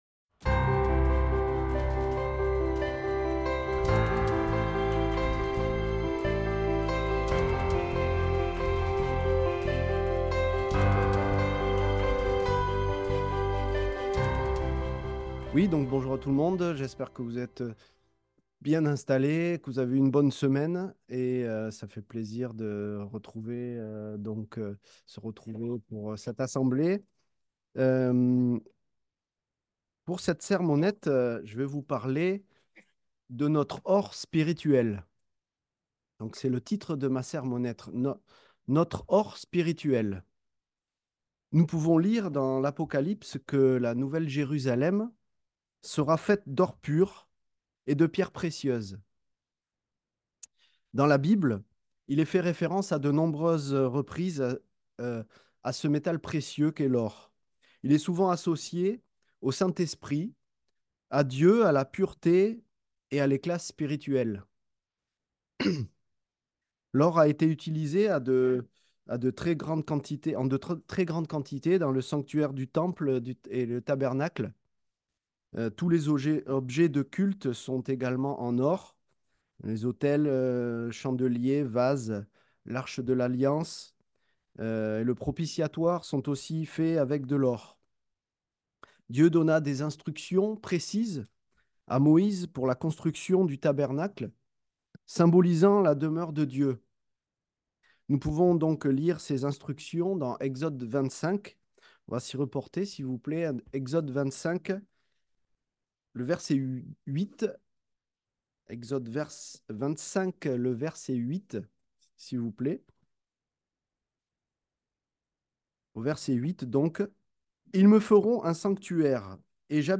Sermons
Given in Bordeaux